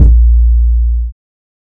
Waka 808 - 2 (4).wav